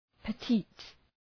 Προφορά
{pə’ti:t}